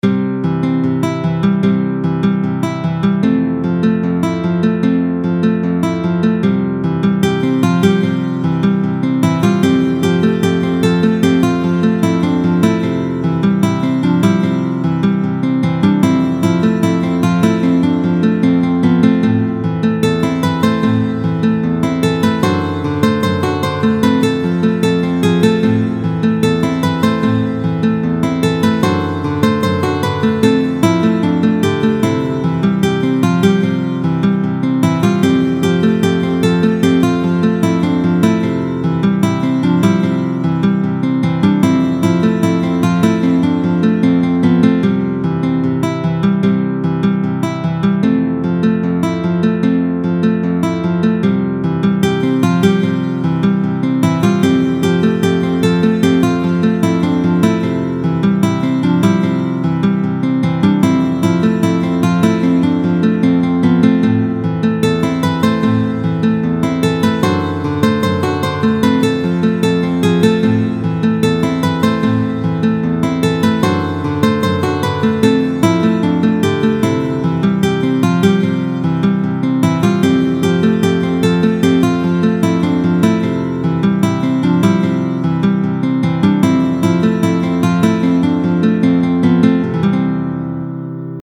Tuto guitare